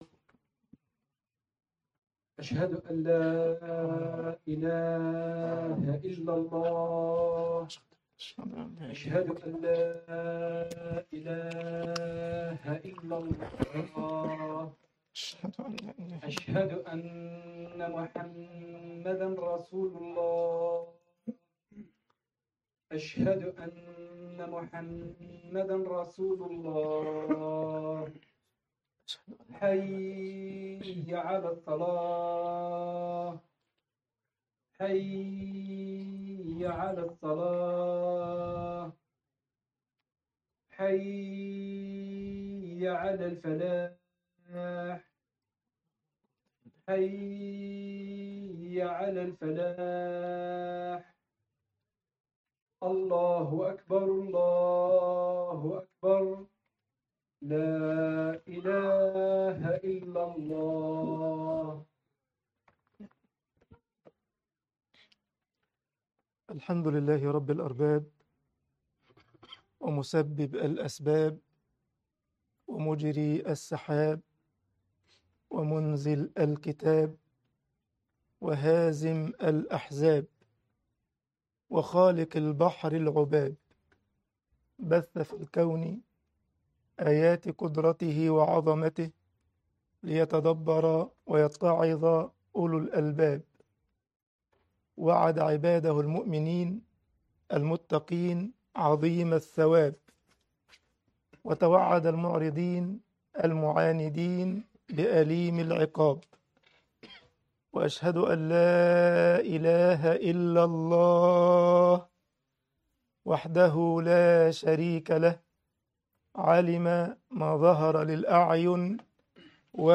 خطبة الجمعة صيام الستة من شوال